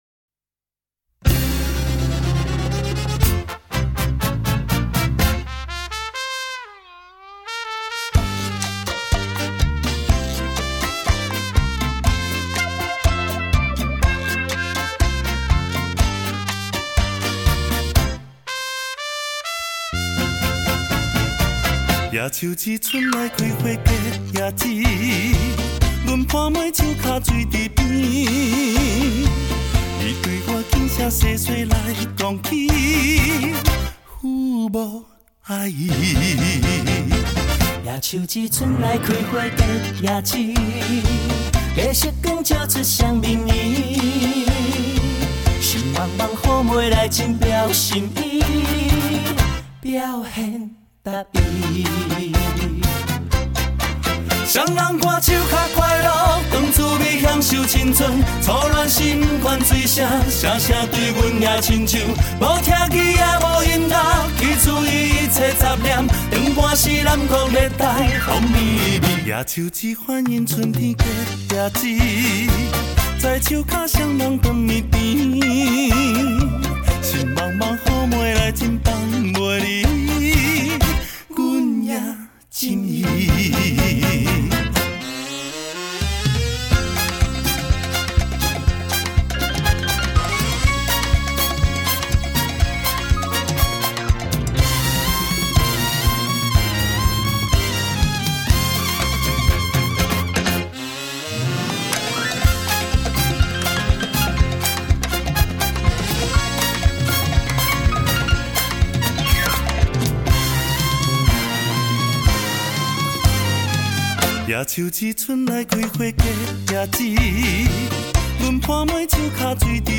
09.爱情限时批 - 大对唱